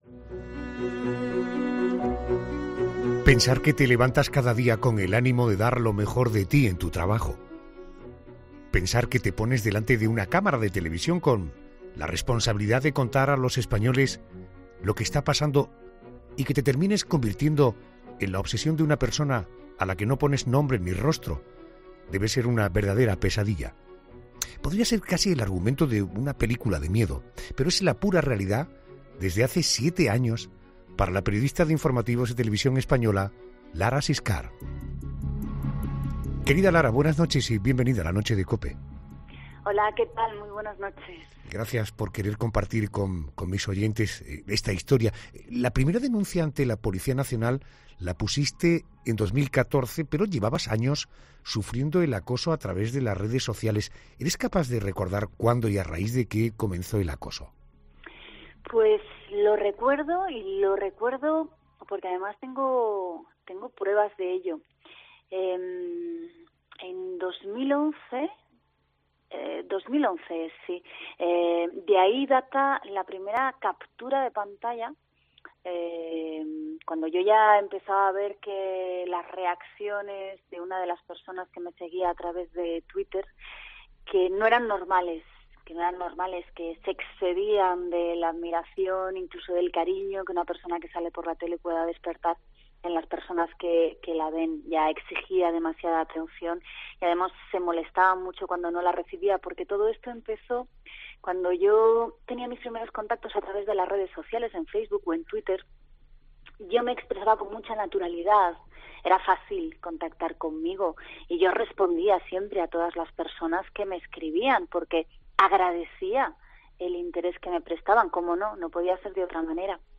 Lara Siscar, periodista de informativos de TVE, ha pasado por los micrófonos de 'La Noche' con Adolfo Arjona, para hablar sobre el problema que...